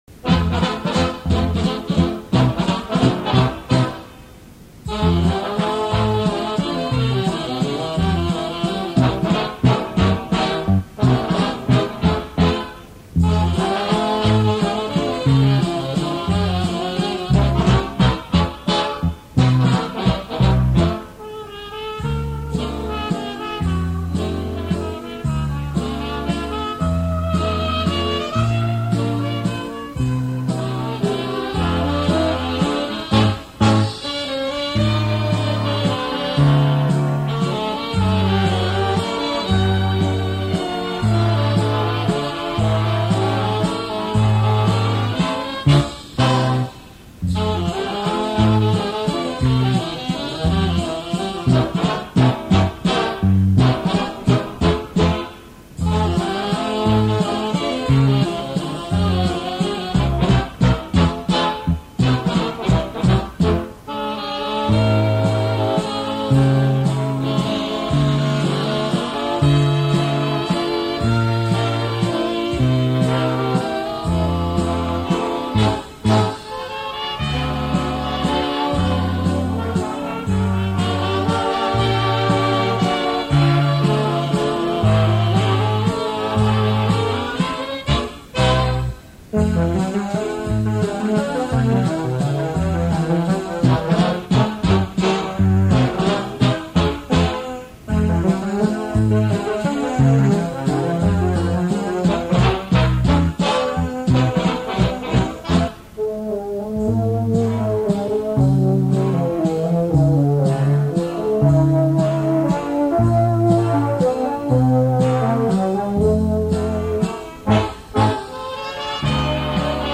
• Category: Waltz